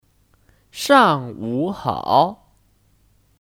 上午好 Shàngwǔ hǎo : Selamat pagi